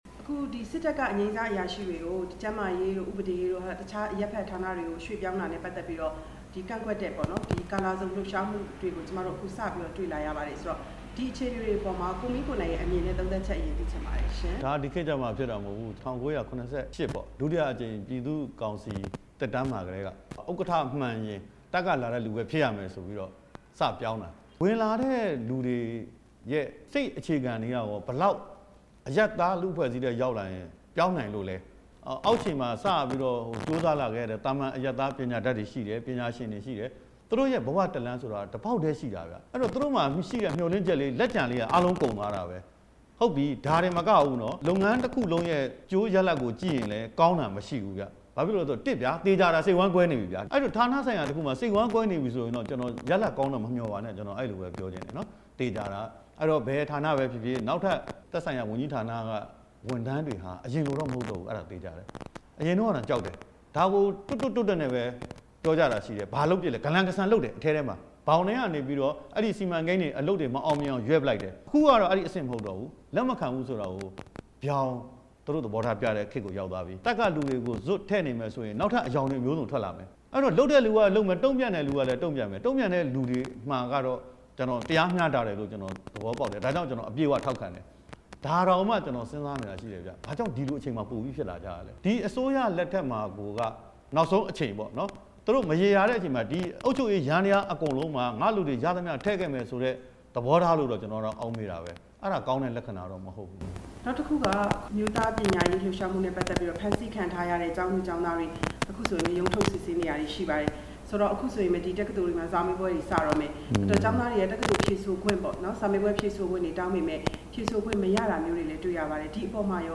ဖဲကြိုးလှုပ်ရှားမှုတွေအကြောင်း ကိုမင်းကိုနိုင်နဲ့ မေးမြန်းချက်